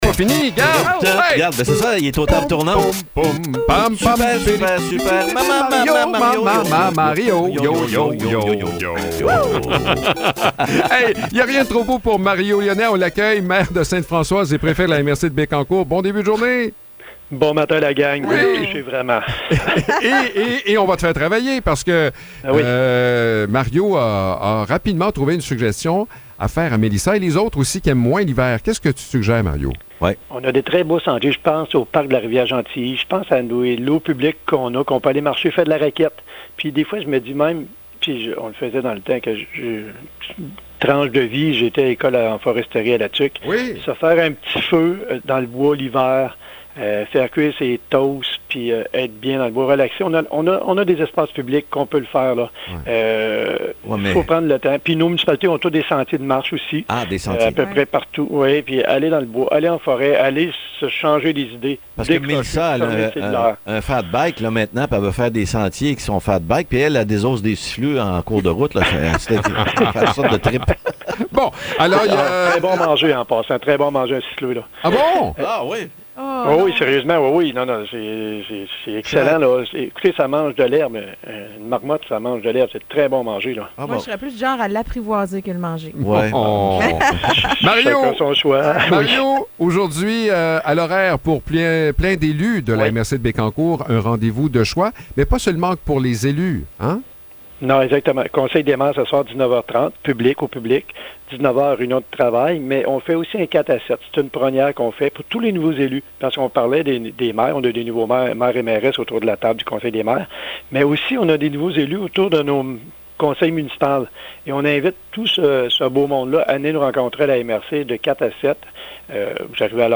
Mario Lyonnais, maire de Sainte-Françoise et préfet de la MRC de Bécancour, partage ses suggestions pour mieux apprécier l’hiver. Il en profite aussi pour inviter la population à un 4 à 7 visant à démystifier le rôle et le travail de la MRC. Enfin, il souligne qu’un dossier plus préoccupant demeure bien présent dans la région : la fraude, qui continue malheureusement de toucher des gens près de chez nous.